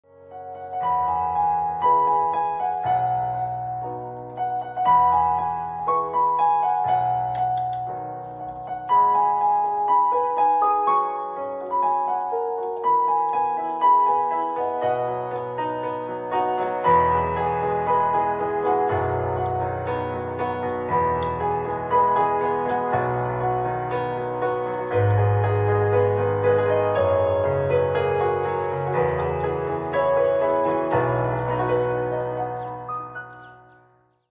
6TypeInstrumental / Background Music Tone
• Peaceful and soothing instrumental music
No, it is a pure instrumental version without vocals.